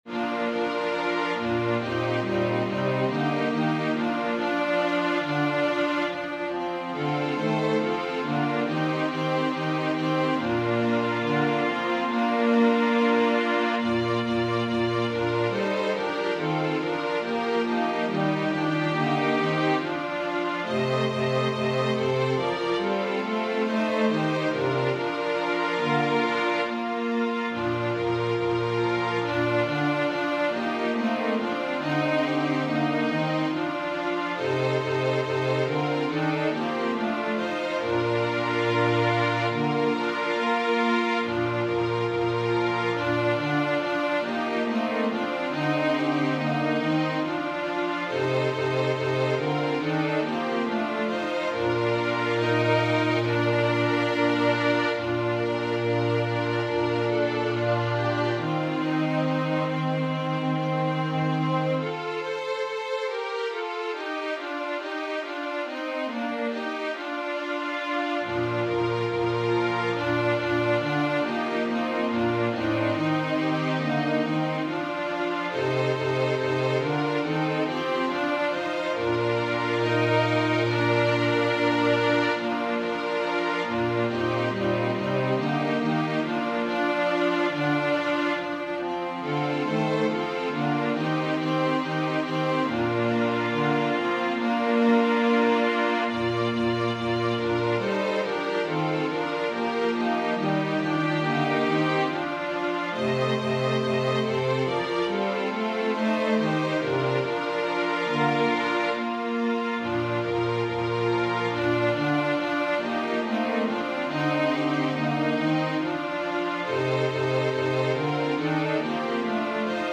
Chants classiques Téléchargé par